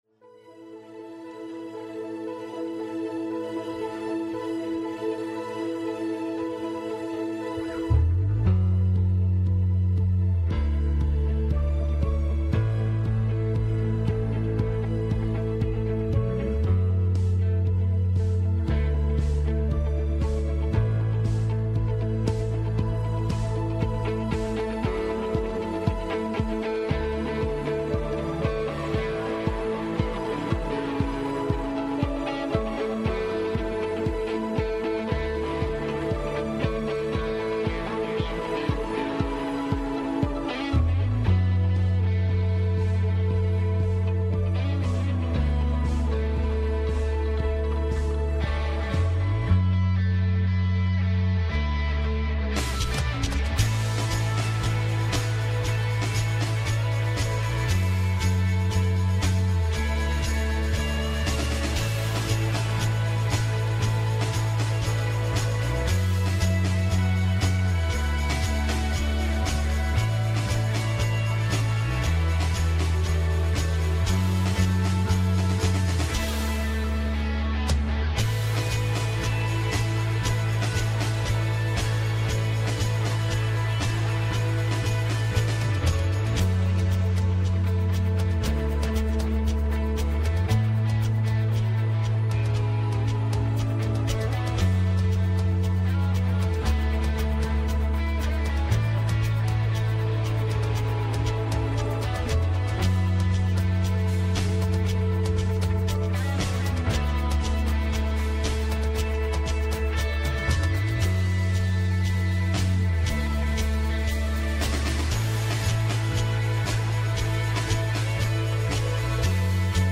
Westgate Chapel Sermons Resolute: Broken to Beautiful - John 21:9-17 Apr 27 2025 | 01:25:47 1x 00:00 / 01:25:47 Subscribe Share Apple Podcasts Overcast RSS Feed Share Link Embed